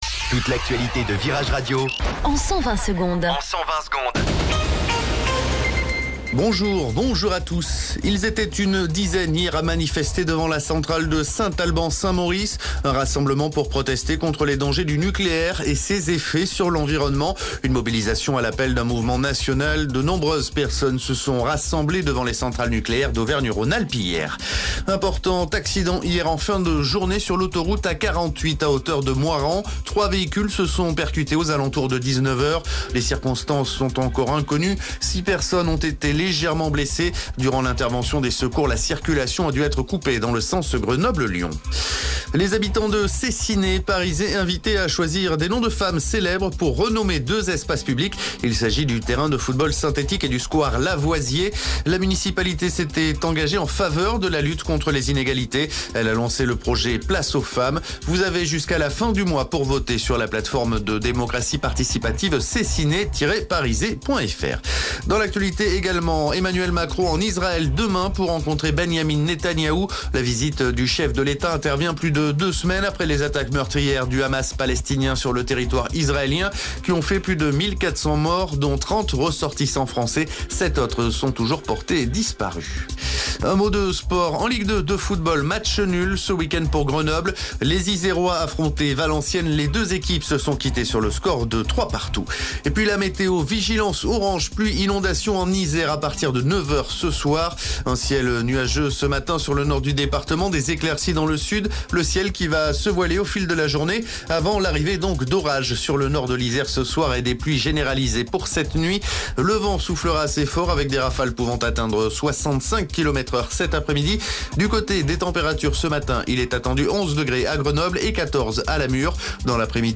Flash Info Grenoble 23 Octobre 2023 Du 23/10/2023 à 07h10 Flash Info Télécharger le podcast Partager : À découvrir The Strokes : Un nouvel album pour dynamiter 2026, REALITY AWAITS !